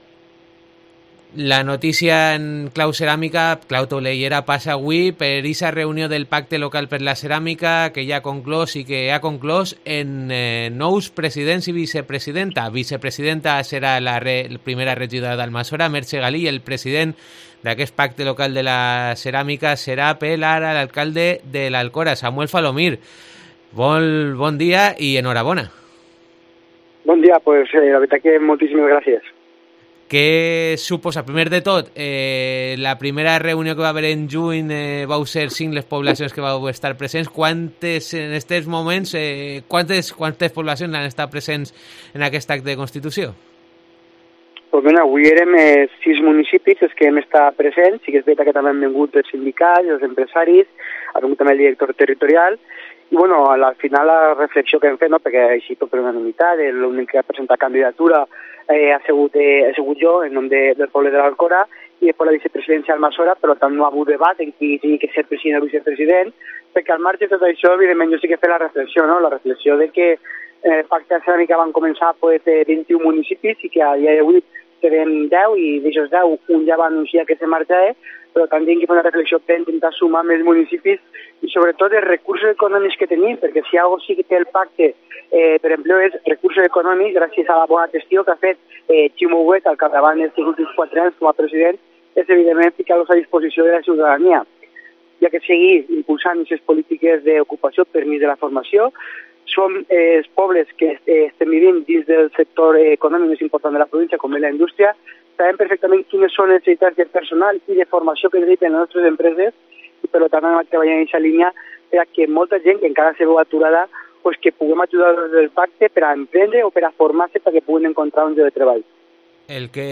El alcalde de L'Alcora ha apuntado en COPE que confía en poder conseguir la participación de otras poblaciones importantes para el sector como Betxí y Vilafamés, aunque ya destaca poder convencer a Vila-real para que no se marche de dicho pacto, apuntando eso sí que siempre tendrán la puerta abierta para volver.